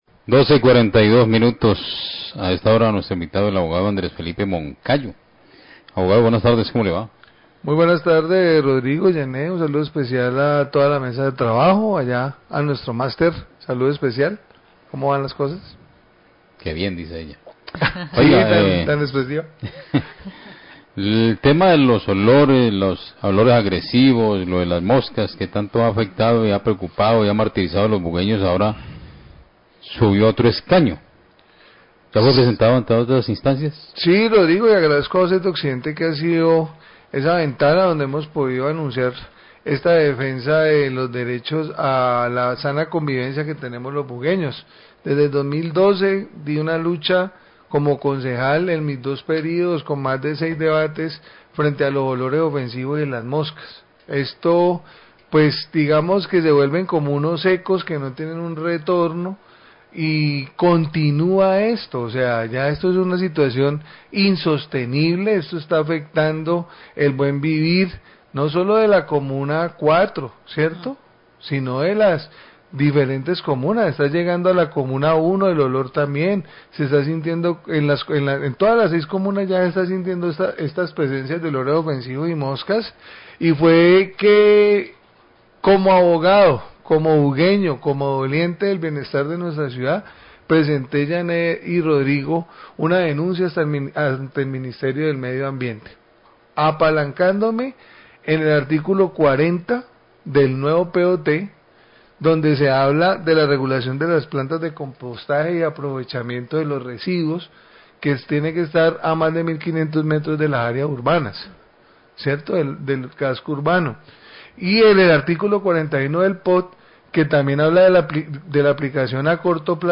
Radio
Mas adelante en la entrevista, recuerda que la CVC interpuso unas acciones contra los responsables.